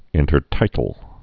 (ĭntər-tītl)